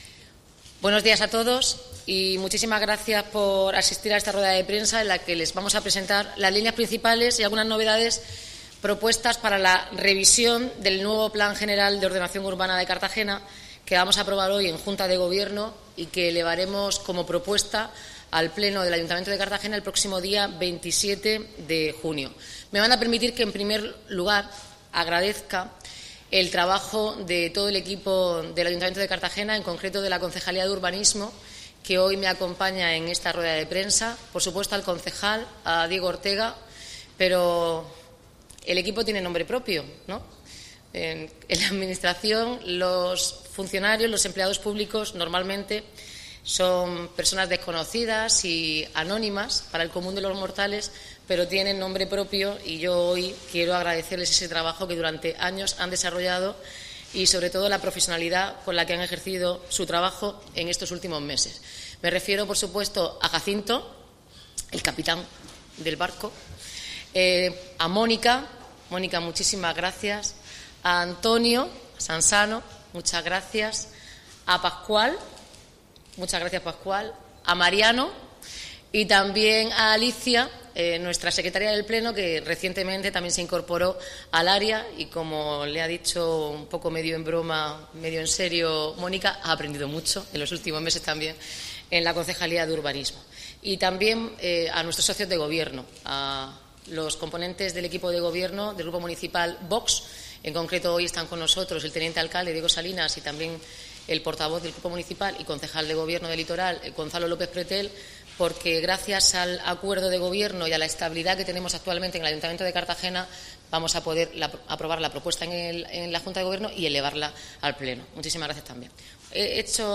Noelia Arroyo explica que habr� un crecimiento ordenado de Cartagena y las diputaciones, con protecci�n ante plantas fotovoltaicas y lugares como la estaci�n telegr�fica de Cabo de Palos y la c�rcel de San Ant�n
Audio: Presentaci�n del nuevo PGOU (MP3 - 13,76 MB)